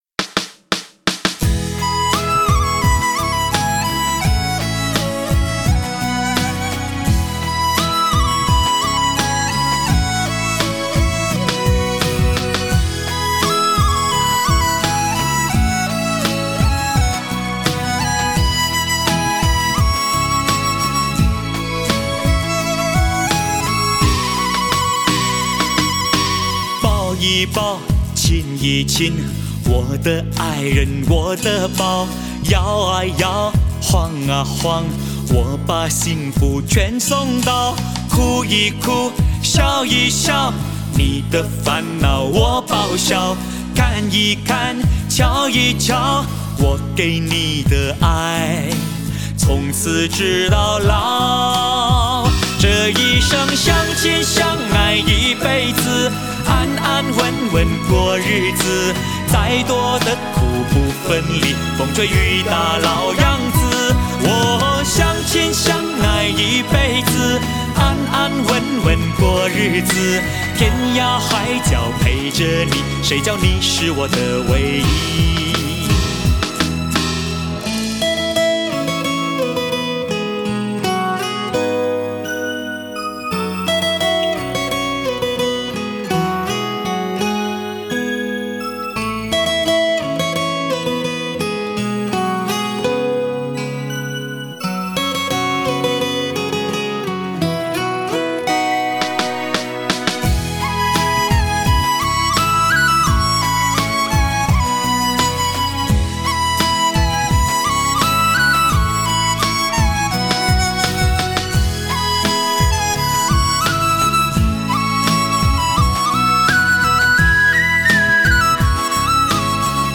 用它欢快的节奏带给我们一幅温馨的画面
略带美声的男中音